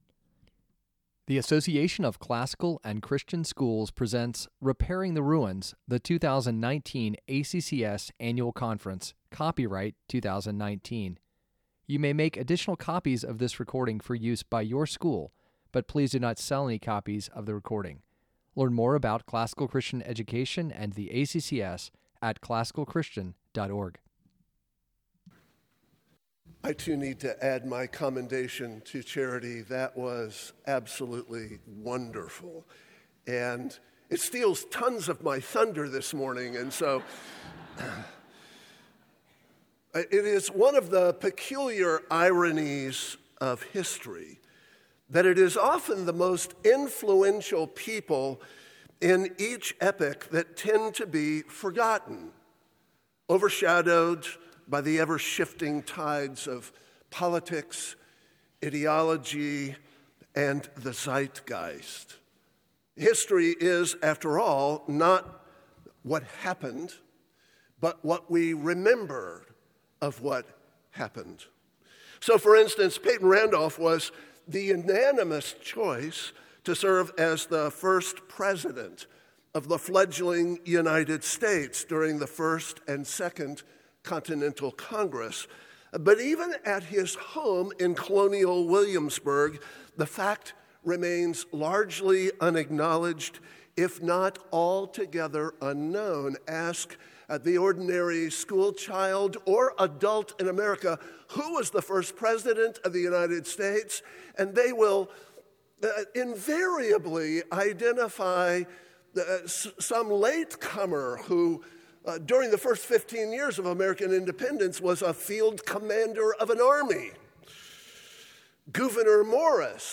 2019 Plenary Talk | 38:02 | All Grade Levels, Culture & Faith
Additional Materials The Association of Classical & Christian Schools presents Repairing the Ruins, the ACCS annual conference, copyright ACCS.